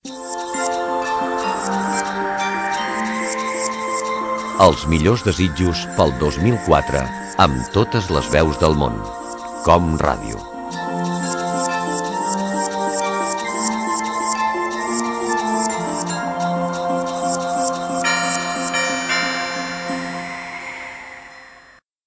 Felicitació de Nadal "Totes les veus del món".
Divulgació